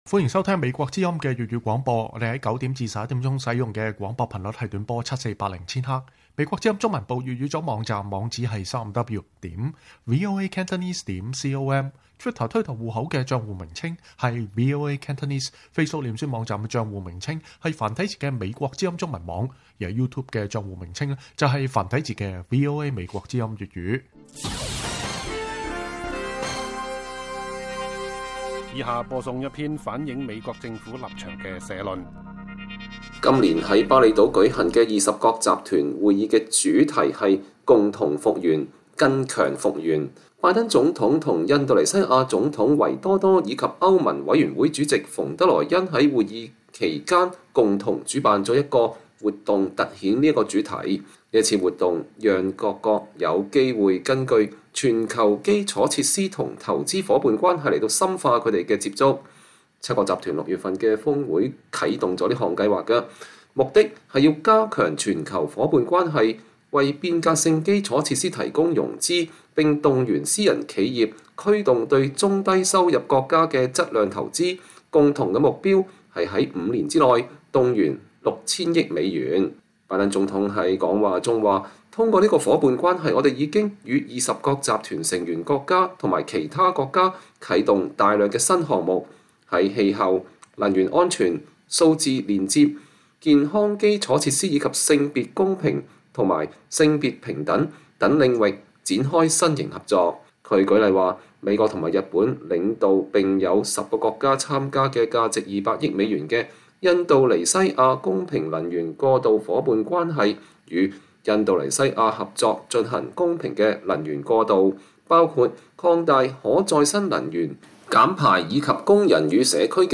美國政府政策立場社論：拜登總統在巴厘島稱讚夥伴關係的進展